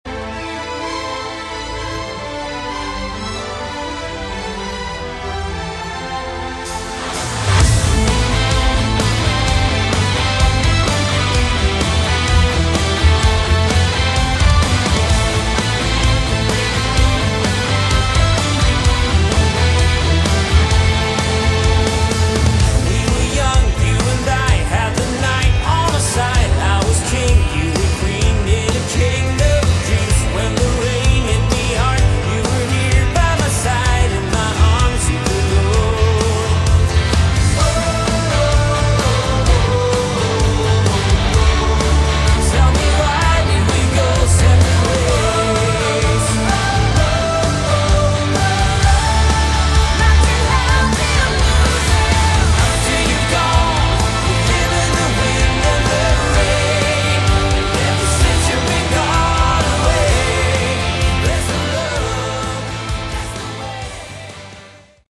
Category: Melodic Rock
lead vocals, guitars
keyboards, backing vocals
recorded at Vaasa, Elisa Stadium in Finland in summer 2022.